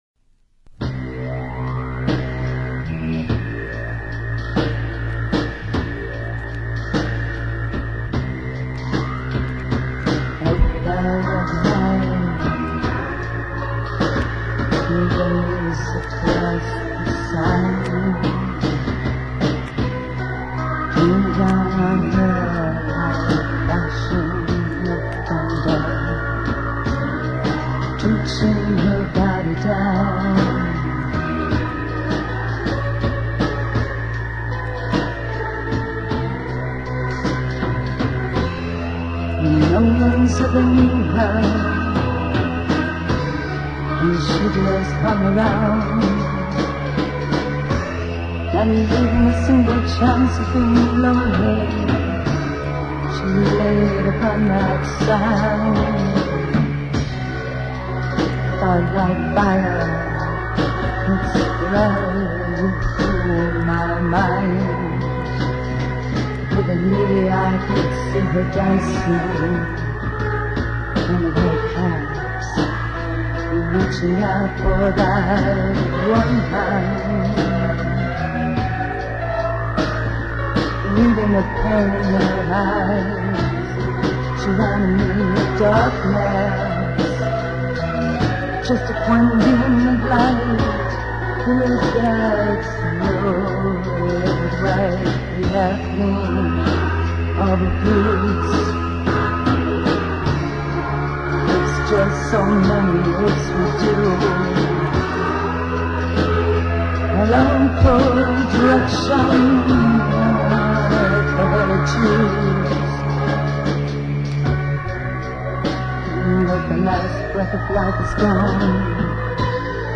Alternative Funk/Soul Rock